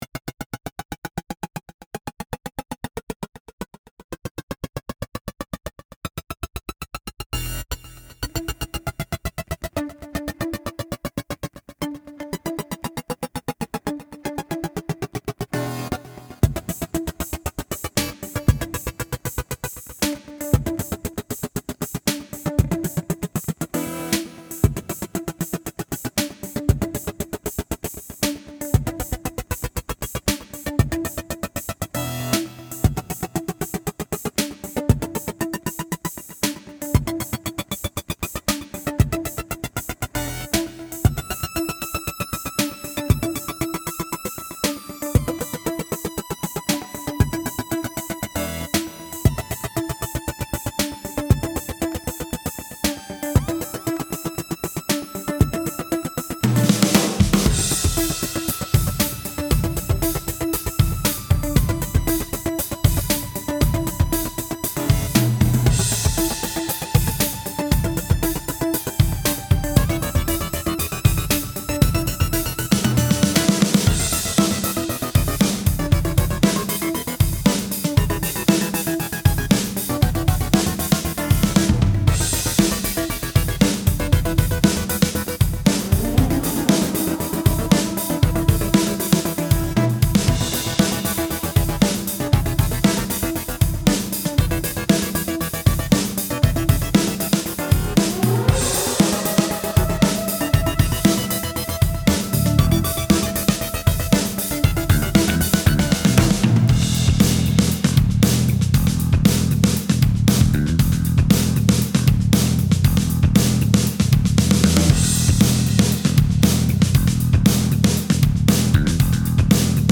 Drum Kit,  Electronic Drums, Electronic Percussion.